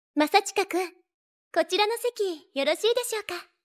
数据集都是已经处理过的数据，可以拿过来训练自己的模型，去除了背景噪音等，都是手工切分，数据很准！